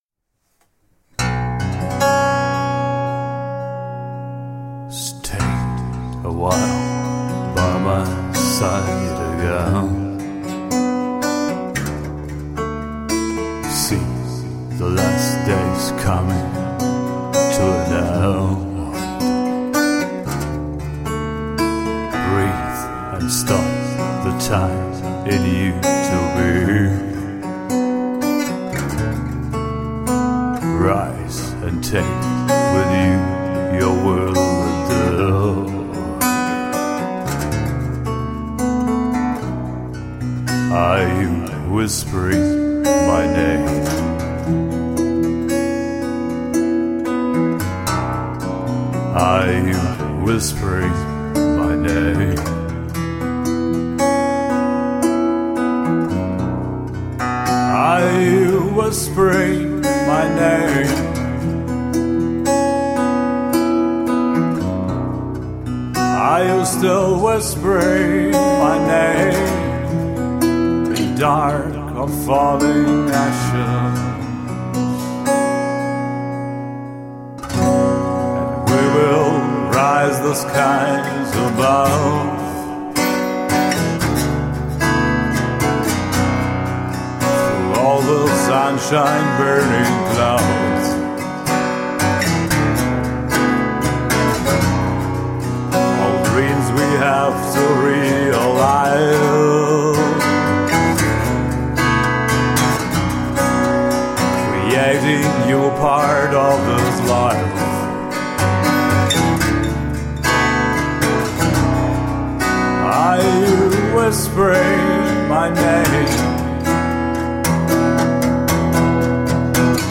Pure soulful and raw acoustic songwriter.
Tagged as: Alt Rock, Darkwave, Goth, Indie Rock